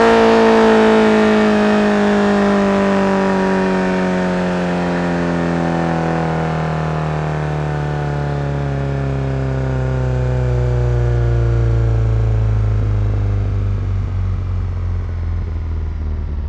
rr3-assets/files/.depot/audio/Vehicles/i4_06/i4_06_decel.wav